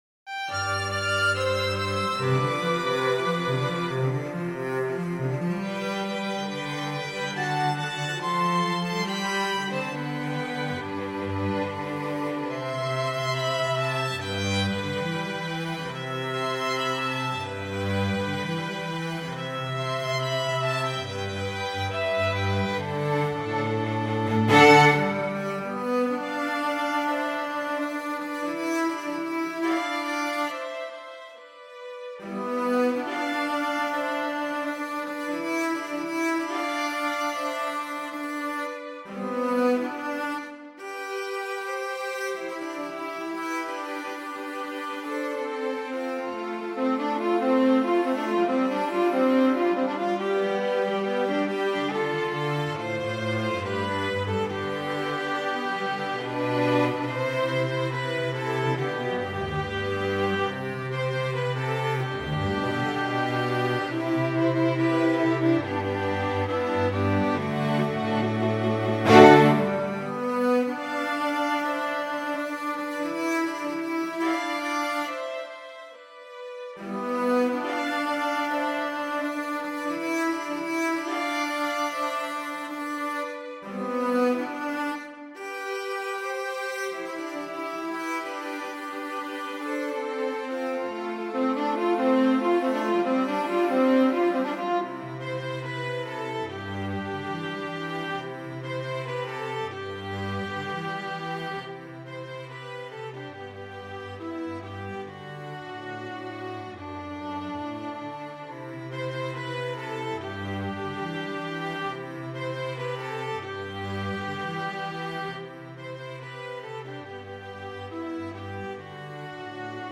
String Trio